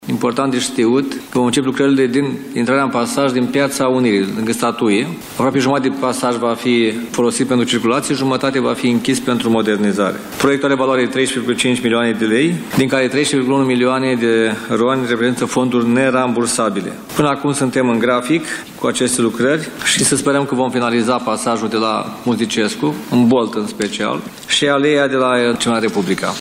Anunţul a fost făcut, astăzi, de primarul Iașului, Gheorghe Nichita. Acesta a prezentat, în cadrul unei conferințe de presă, stadiul proiectelor realizate, în municipiu, cu fonduri europene.